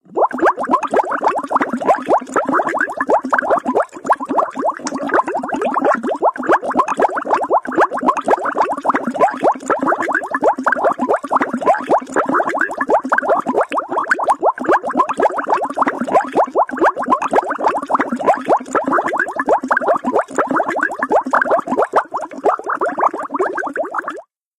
｢ぶくぶくぶく…」といった水が沸騰した時の音です。
沸騰したお湯 着信音